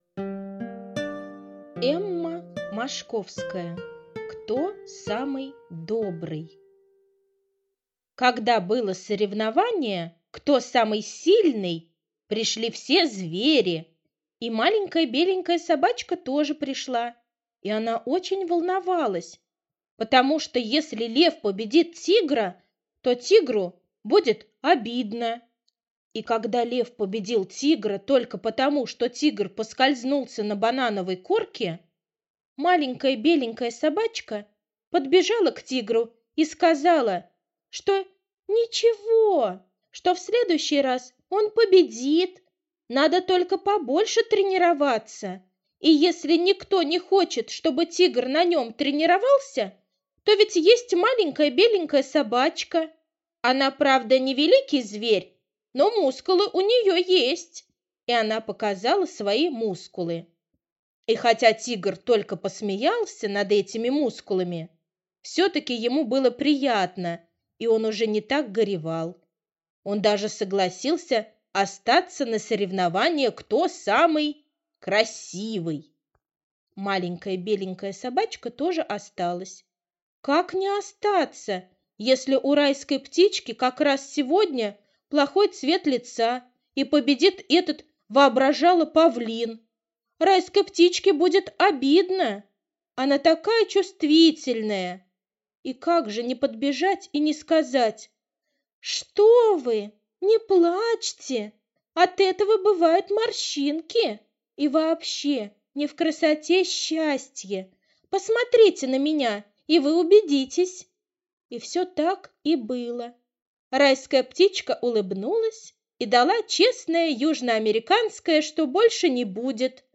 Кто самый добрый - аудиосказка Мошковской Э.Э. Сказка про Маленькую Беленькую Собачку, которая переживала за зверей, которым было плохо.